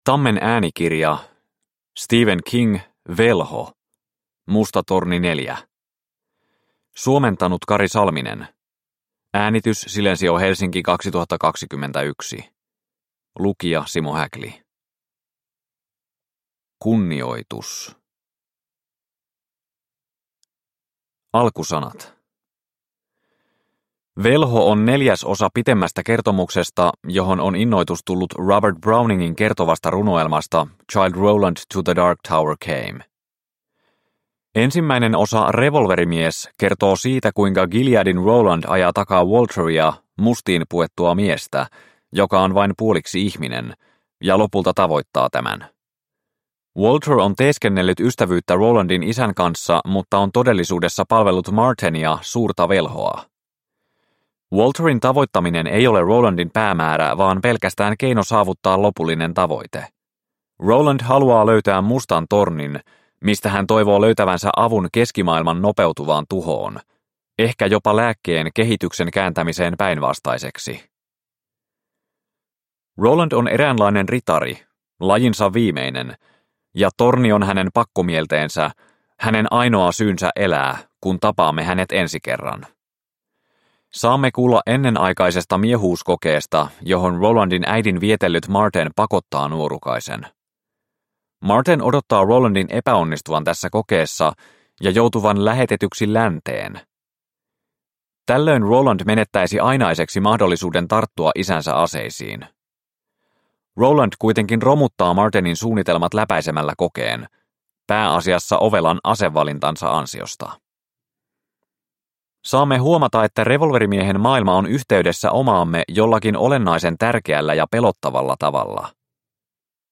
Velho – Ljudbok – Laddas ner